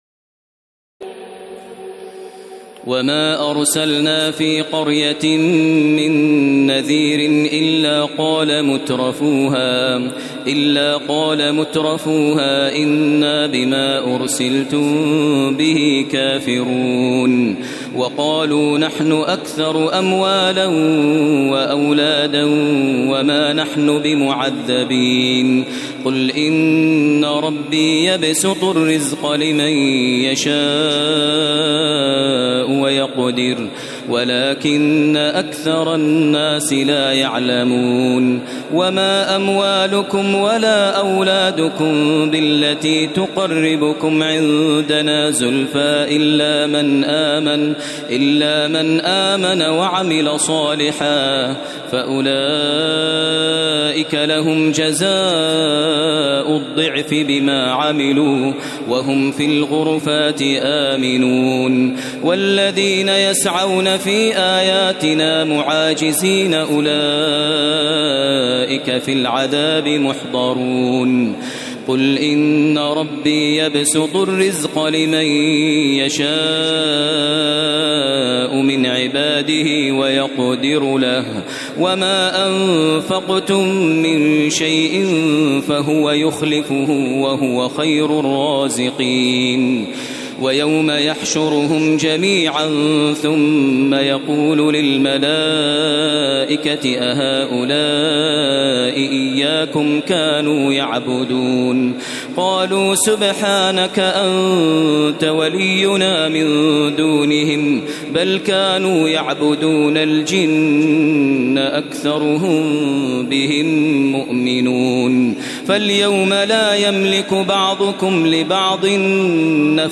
تراويح ليلة 21 رمضان 1432هـ من سور سبأ (34-54) وفاطر و يس(1-32) Taraweeh 21 st night Ramadan 1432H from Surah Saba and Faatir and Yaseen > تراويح الحرم المكي عام 1432 🕋 > التراويح - تلاوات الحرمين